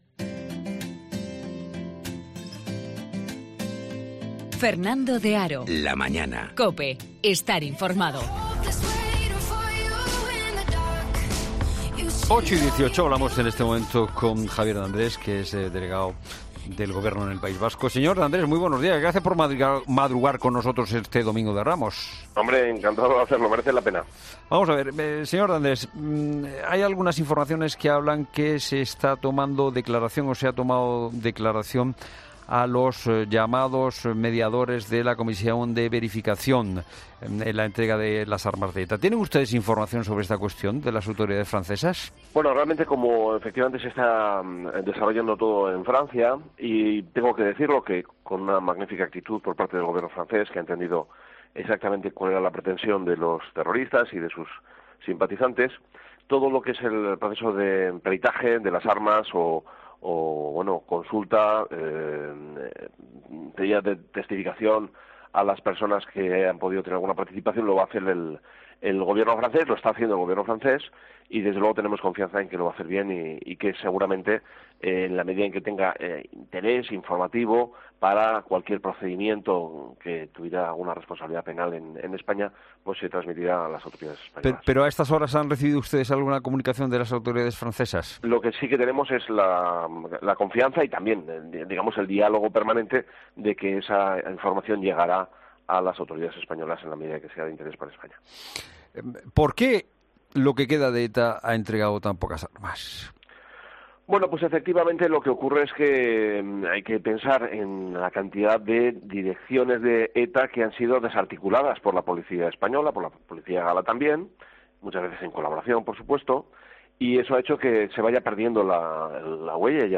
El delegado del Gobierno, Javier de Andrés en 'La mañana fin de semana'